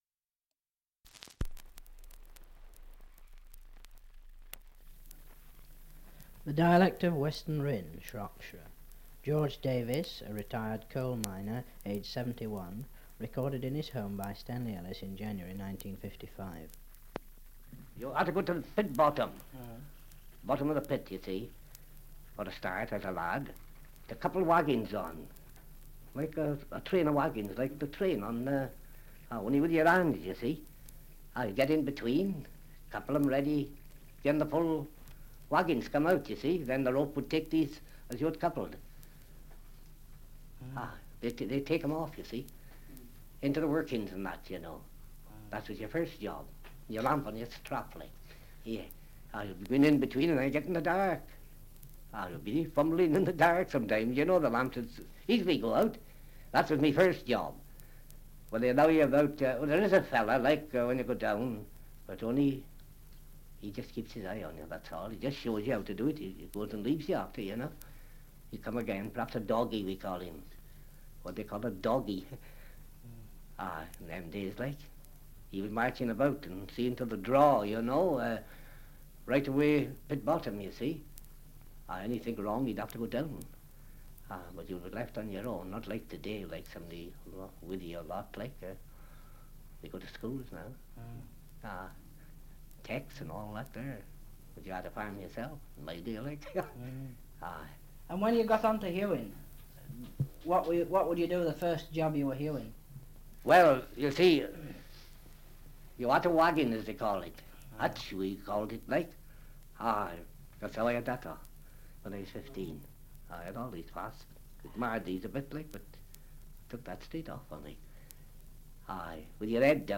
Survey of English Dialects recording in Weston Rhyn, Shropshire
1 - Survey of English Dialects recording in Weston Rhyn, Shropshire
78 r.p.m., cellulose nitrate on aluminium